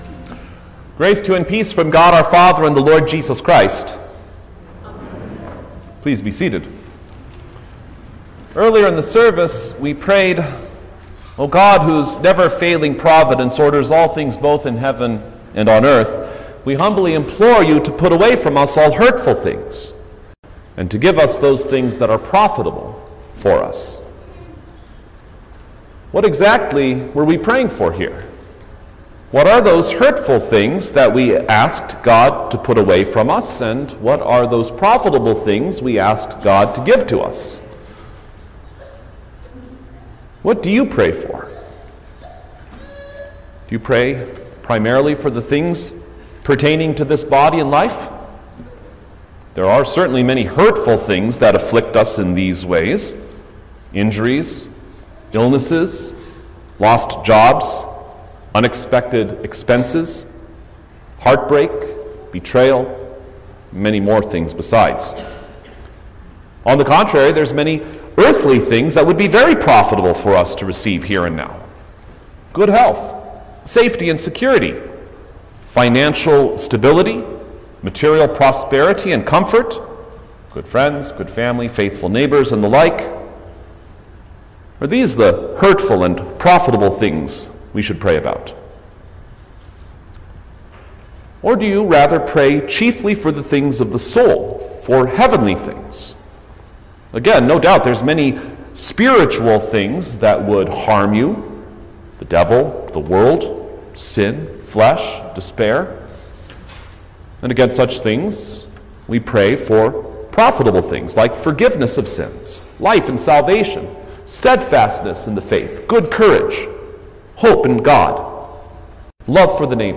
August-3_2025_Seventh-Sunday-after-Trinity_Sermon-Stereo.mp3